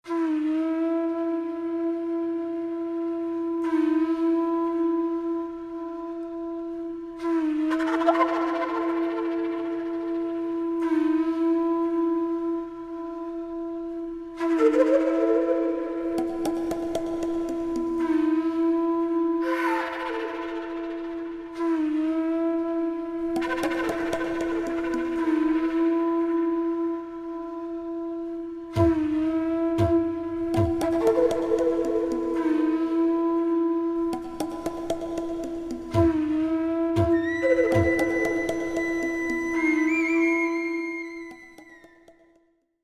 Genre: World Fusion.
bansuri
It features three bansuris recorded on eight tracks.